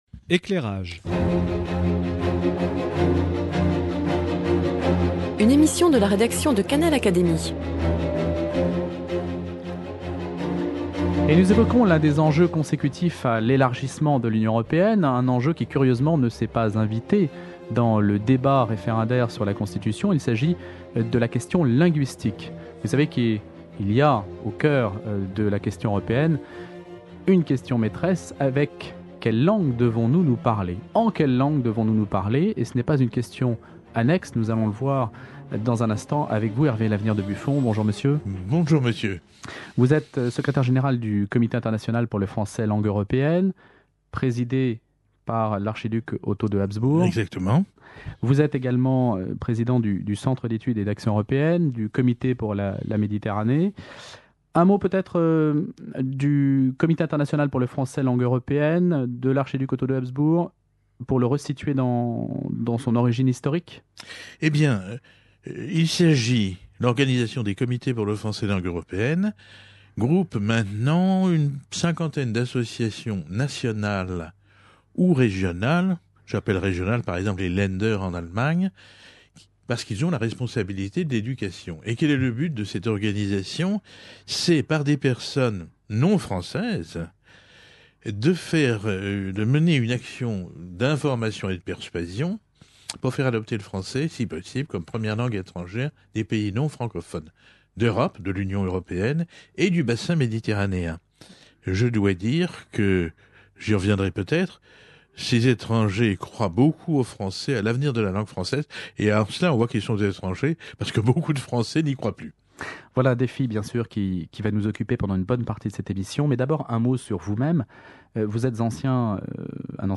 Il explique ses raisons au micro de Canal Académie.